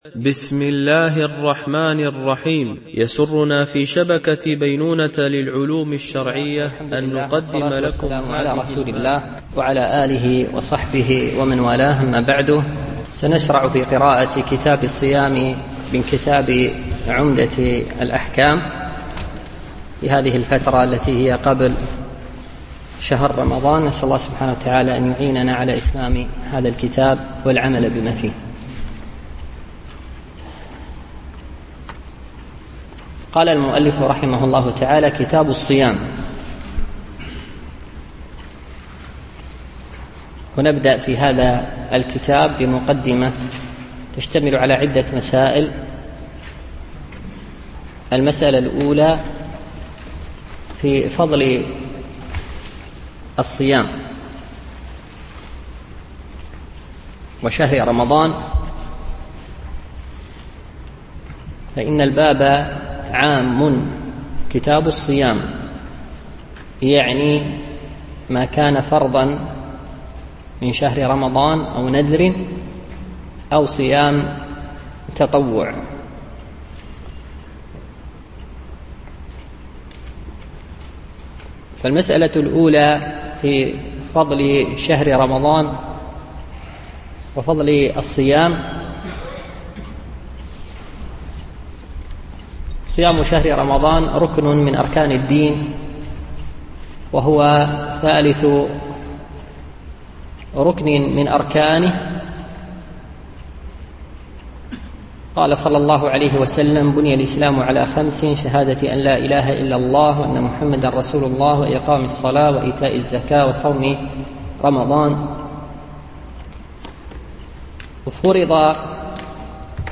مسجد المهاجرين مدينة المرفأ
MP3 Mono 22kHz 32Kbps (CBR)